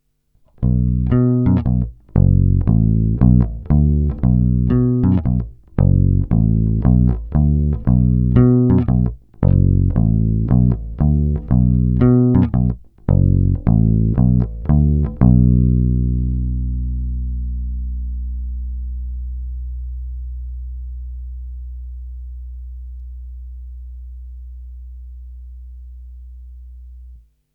Zvuk asi mnohým sedět nebude, má výrazný kontrabasový charakter, kratší sustain, ale jinak je zamilováníhodný.
Není-li řečeno jinak, následující nahrávky jsou vyvedeny rovnou do zvukovky a kromě normalizace ponechány bez zásahů.
Hra u kobylky – otevřeno